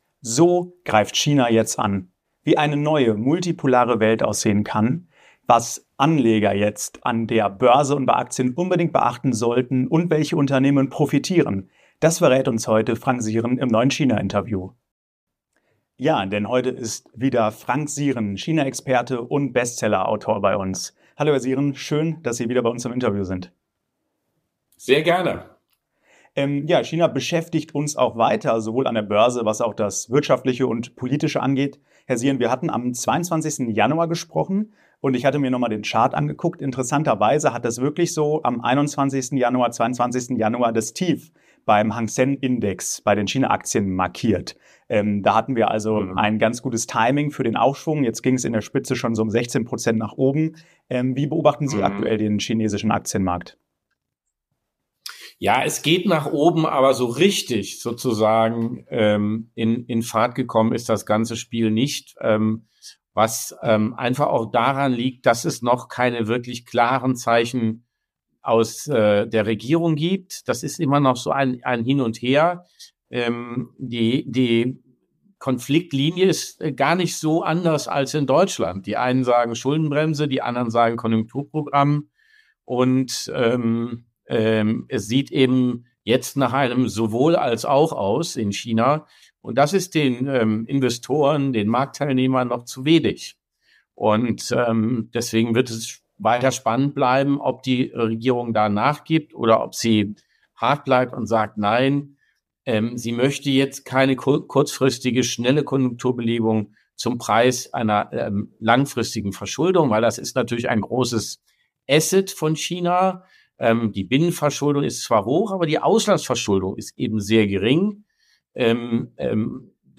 Ist der China-Boom an den Börsen schon vorbei? BÖRSE ONLINE im Talk